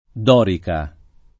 Dorica [ d 0 rika ]